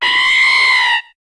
Cri de Delcatty dans Pokémon HOME.